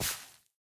brushing_gravel4.ogg